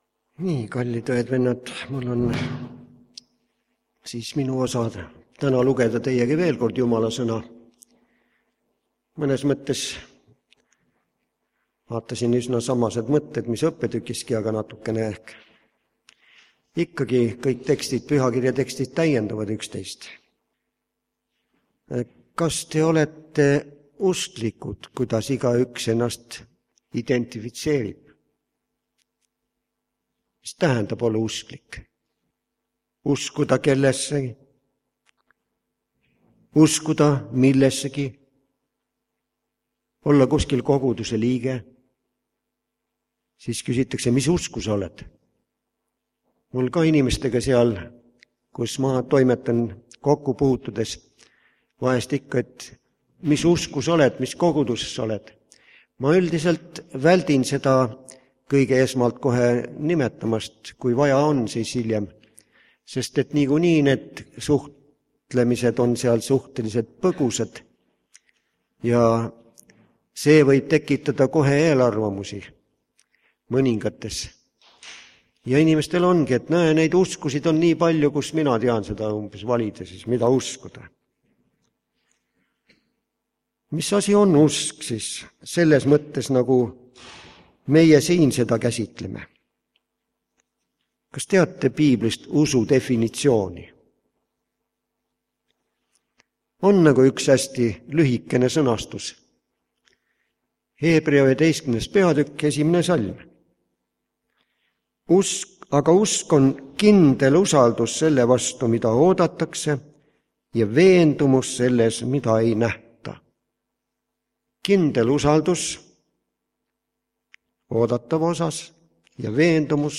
Mis tähendab olla usklik ? Koos laulame laulu 156 Taevase Isa elavad sõnad õiguse valgust maailma tõid.
Jutlused